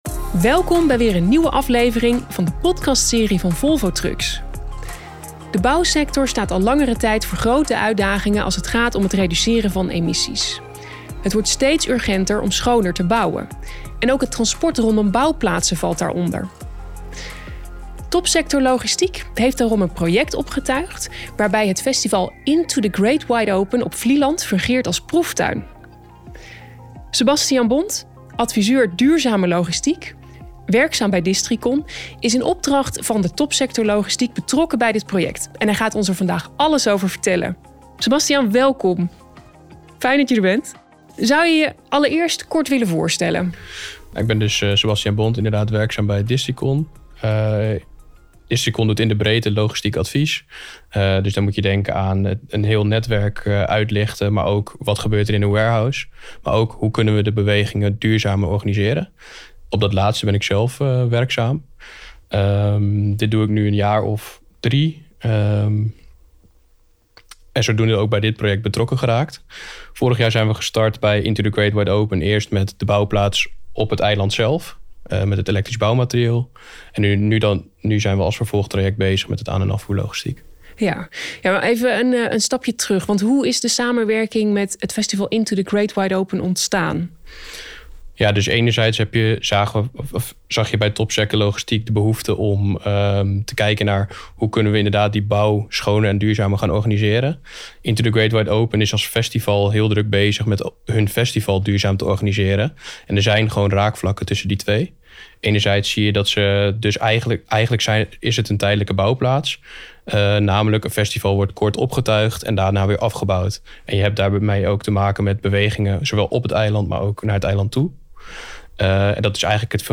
In deze podcastserie gaan we in gesprek met experts over verschillende thema's. Samen gaan we onderweg naar een uitstootvrije toekomst, zonder dodelijke ongevallen in het verkeer en zonder ongeplande stilstand.